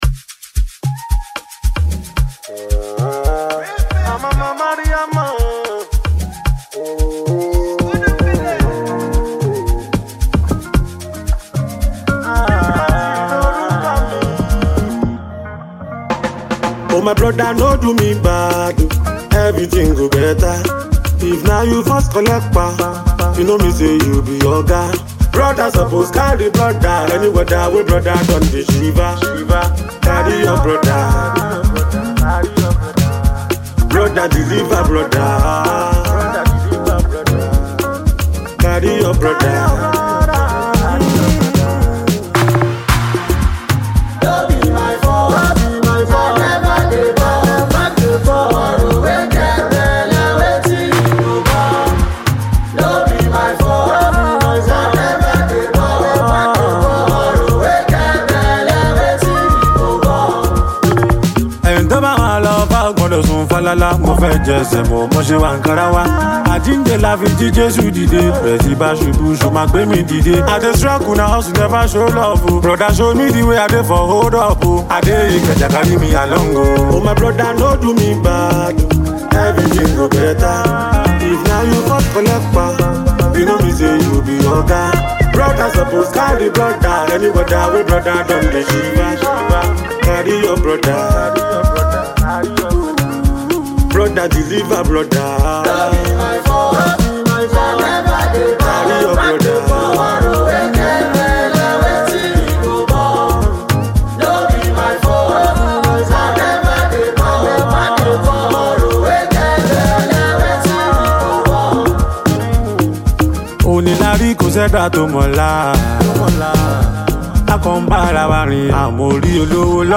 vibrant new song